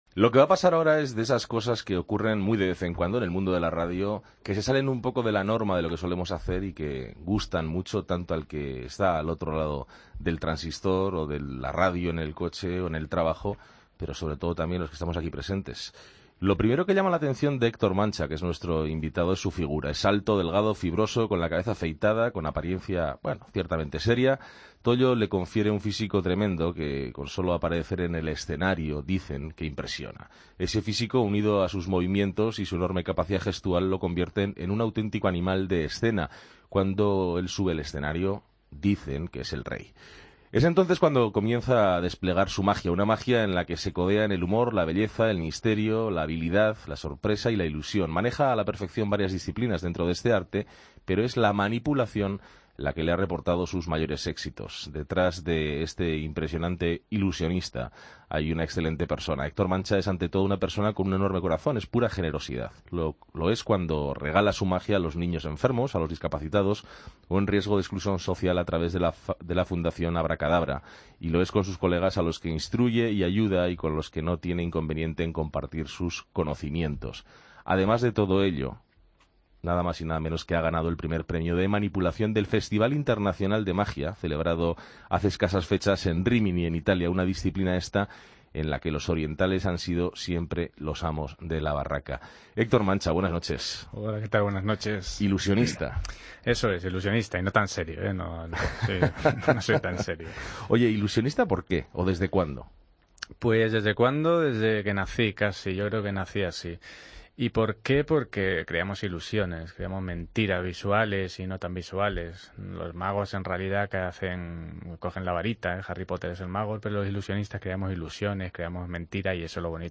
AUDIO: Es uno de los grandes magos del momento y nos ha demotrado en el estudio su habilidad.